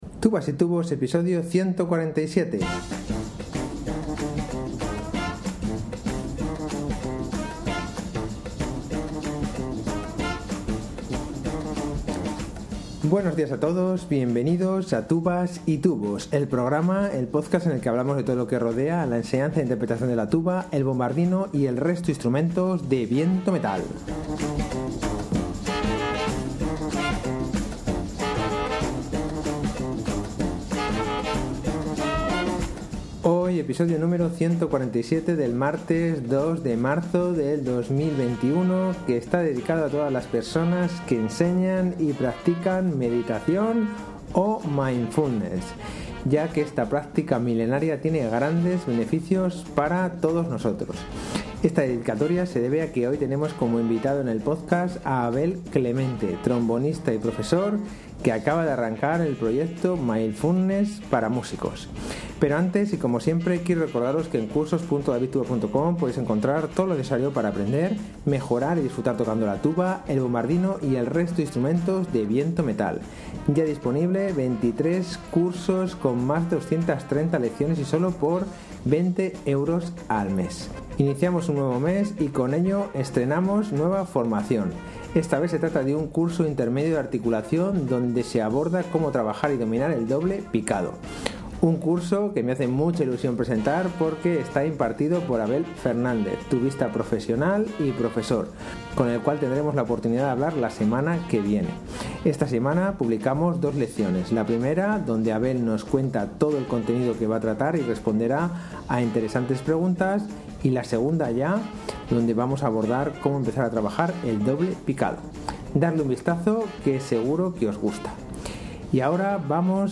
Buenos días a todos, bienvenidos a Tubas y tubos, el programa, el podcast en el que hablamos de todo lo que rodea a la enseñanza e interpretación de la tuba, el bombardino y del resto de instrumentos de viento metal. Hoy, episodio número 147 del martes 2 de marzo de 2021, que está dedicado a todas las personas que enseñan y practican meditación y mindfulness, ya que esta practica milenaria tiene grandes beneficios para las personas.